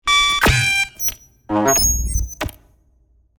This Category features Electronic Glitch Sound Effects perfect for Futuristic, Cybernetics and Digital projects such as Hi-Tech presentations!
Medium-glitching-noise-burst-3.mp3